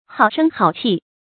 好聲好氣 注音： ㄏㄠˇ ㄕㄥ ㄏㄠˇ ㄑㄧˋ 讀音讀法： 意思解釋： 形容語調柔和，態度溫和。